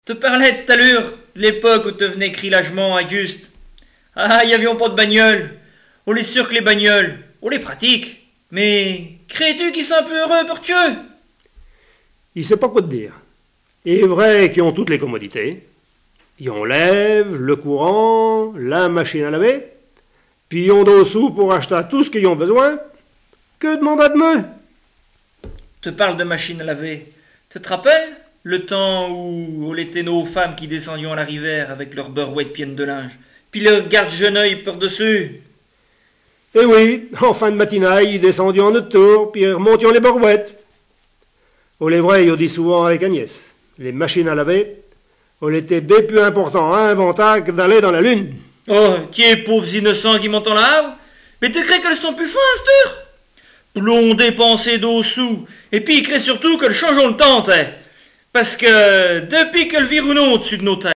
Langue Patois local
Genre sketch